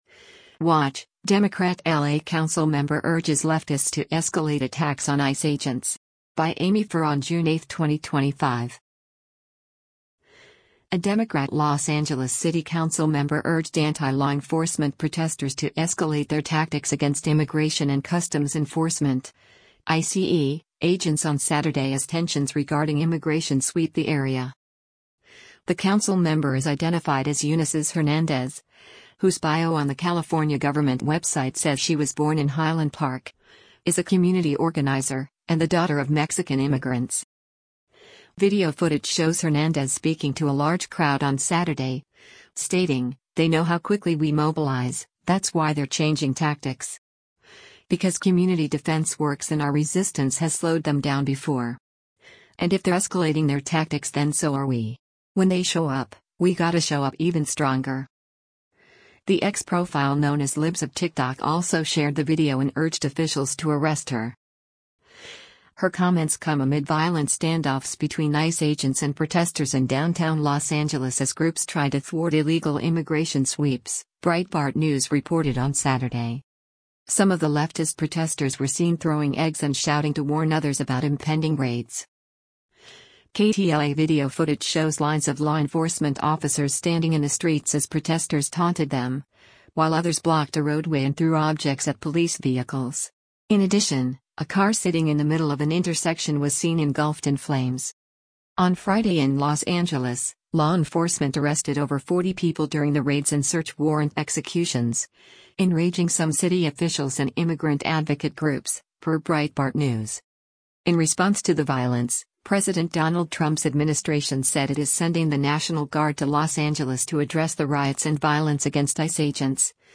Video footage shows Hernandez speaking to a large crowd on Saturday, stating, “They know how quickly we mobilize, that’s why they’re changing tactics. Because community defense works and our resistance has slowed them down before… and if they’re escalating their tactics then so are we. When they show up, we gotta show up even stronger.”